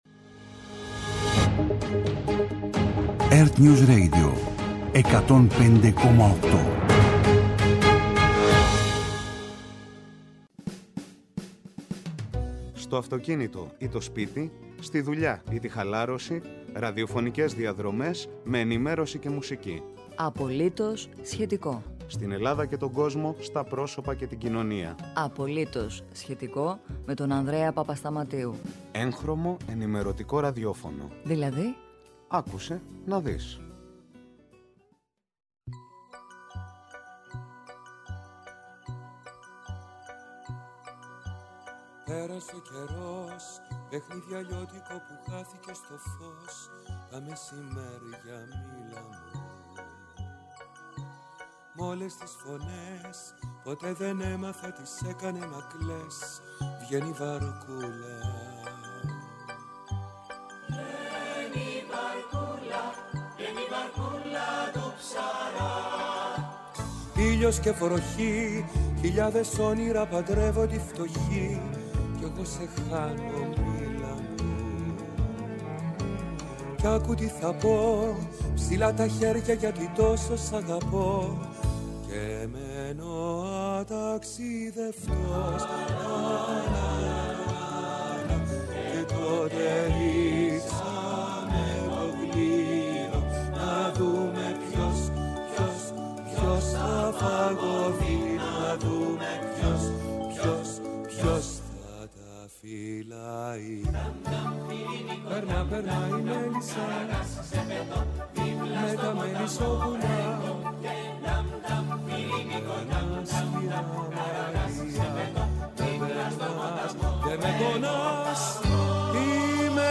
-Μετάδοση ομιλίας Πρωθυπουργού στο Athens Alitheia Forum, στη συζήτηση για τα τα fake news